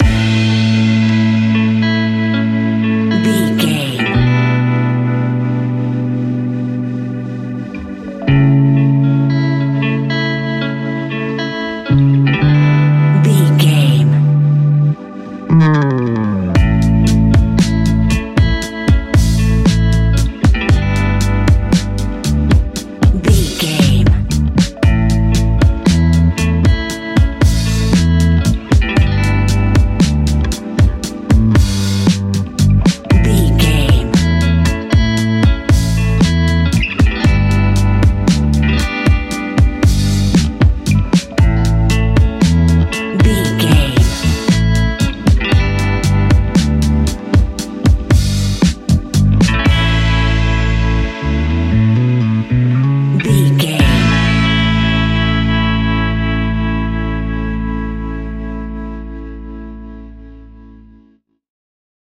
Ionian/Major
laid back
Lounge
sparse
new age
chilled electronica
ambient
atmospheric
morphing
instrumentals